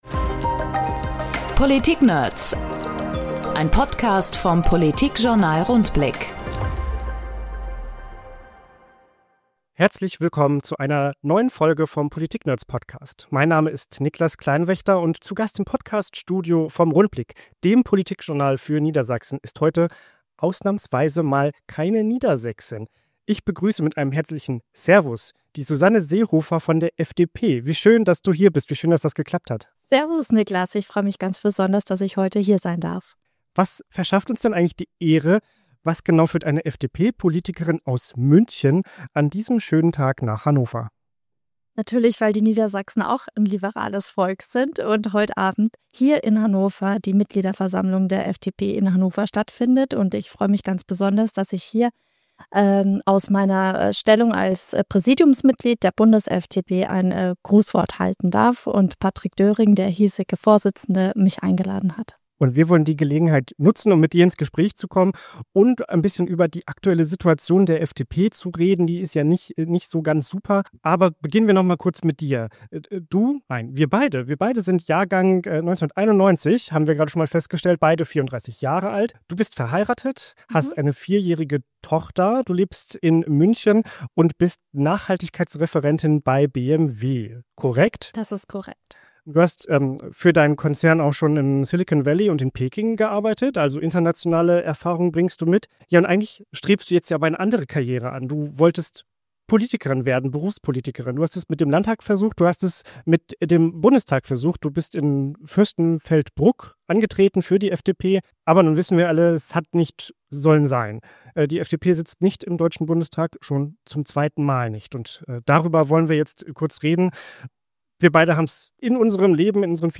In unserem Podcast sprechen die Redakteure des Politikjournals Rundblick mit anderen Politikverrückten: Abgeordneten, Ministerinnen, Interessenvertretern und vielen mehr.